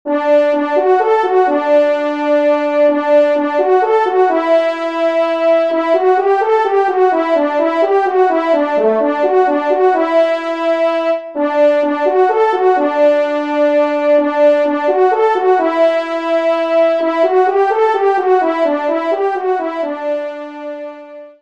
Genre :  Divertissement pour Trompes ou Cors
1ère Trompe